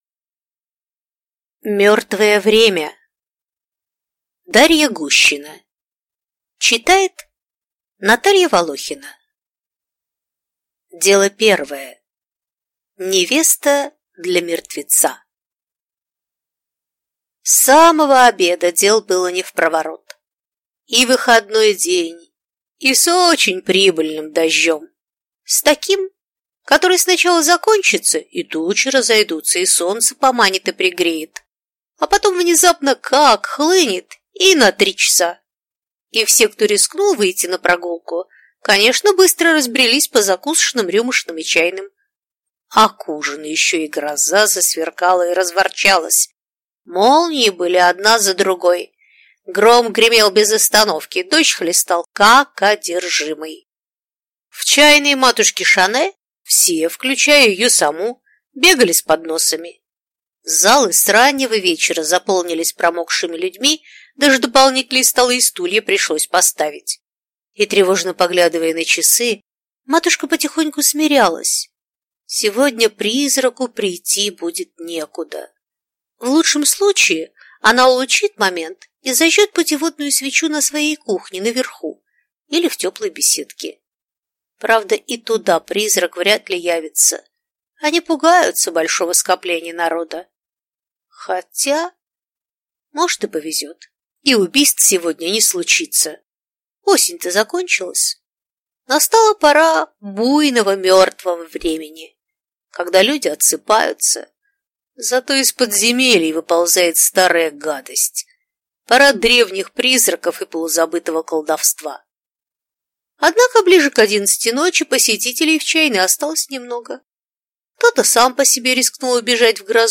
Аудиокнига Мёртвое время | Библиотека аудиокниг
Прослушать и бесплатно скачать фрагмент аудиокниги